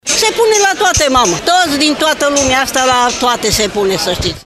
vox-brasov.mp3